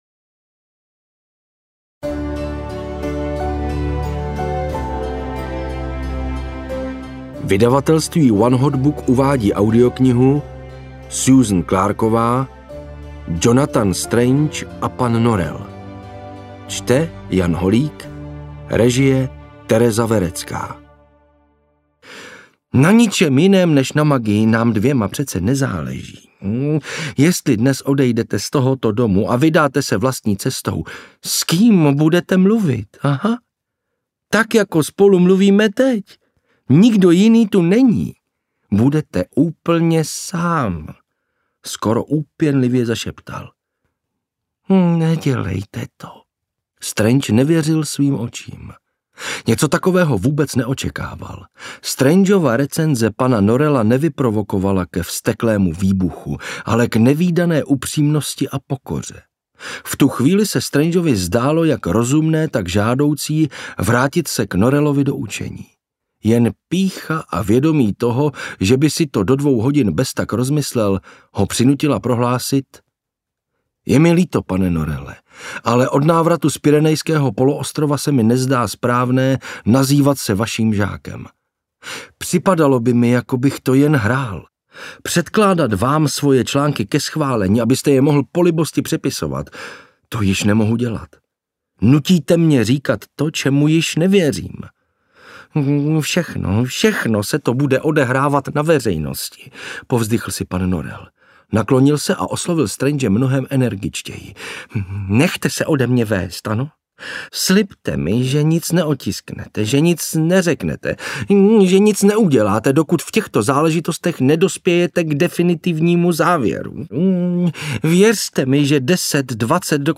Jonathan Strange & pan Norrell audiokniha
Ukázka z knihy